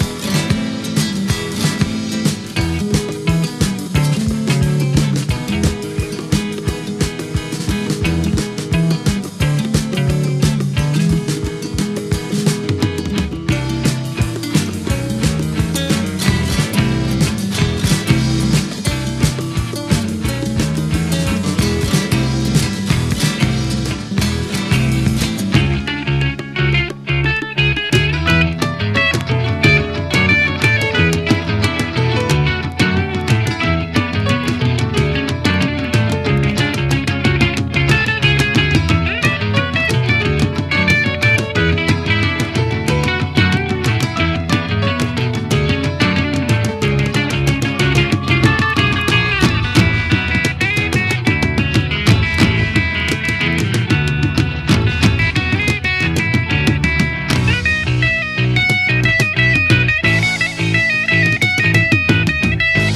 ROCK / 90''S～ / NEO-ACO/GUITAR POP / INDIE POP